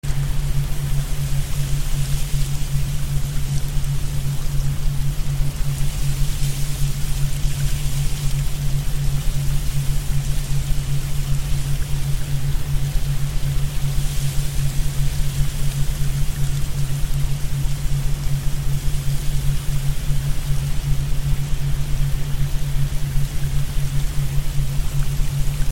PTSD, end trauma response with healing frequencies, subliminals and Ho’oponopono.